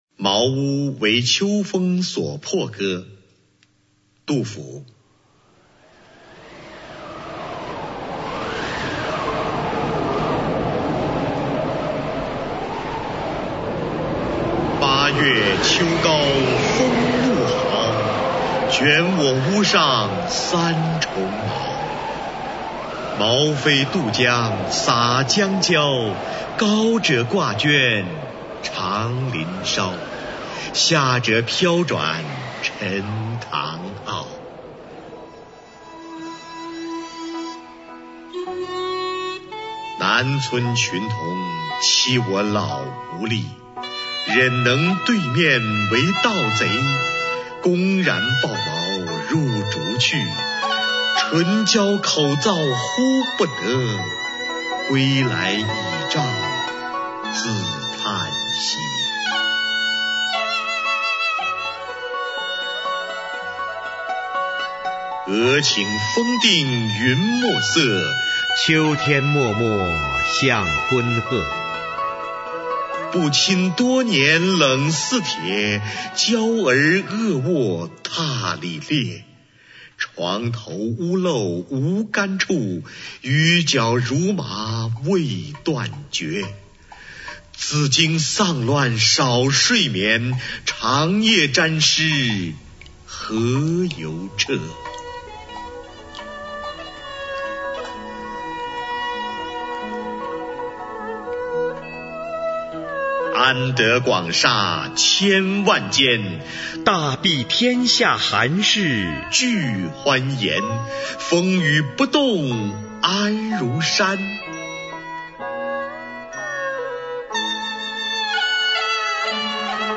《茅屋为秋风所破歌》原文与译文（含赏析、朗读）